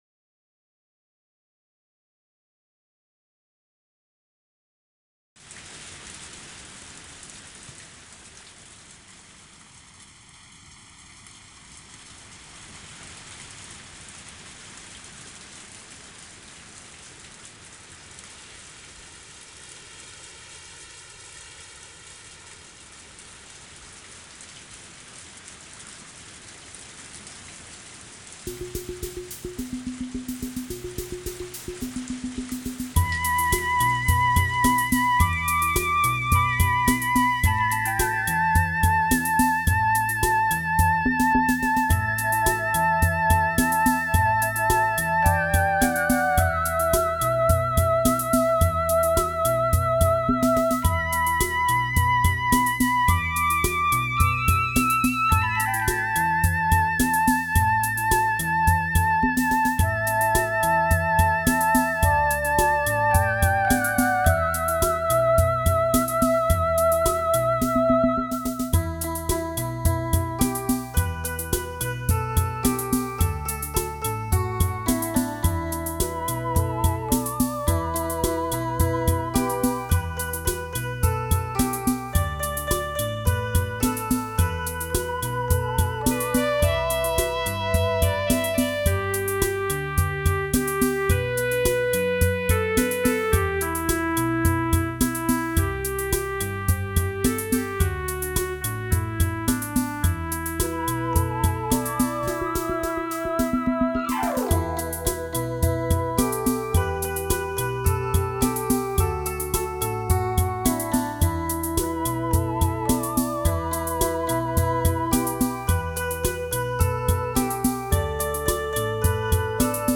本DTS-CD采用最高标准DTS环绕音格式制作而成。保证了音色的清晰度和更宽阔的音域动态范围。